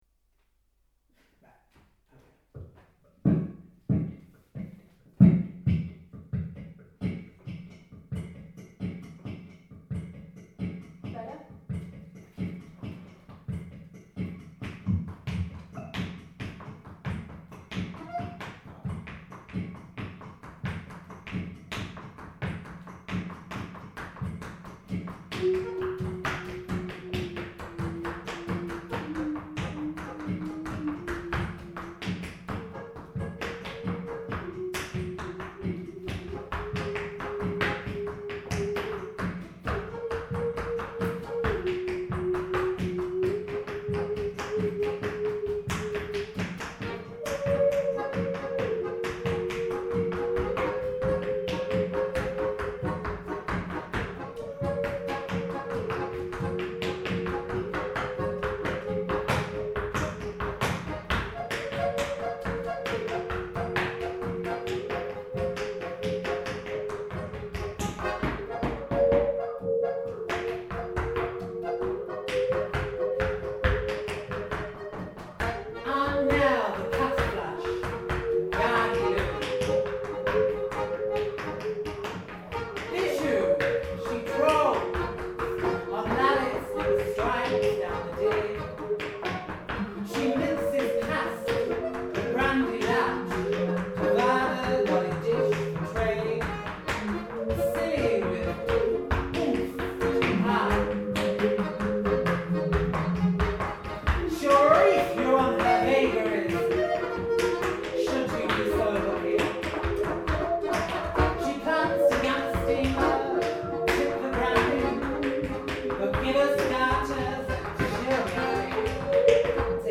Composing groove-based music for the accordion with varying degrees of improvisation - White Rose eTheses Online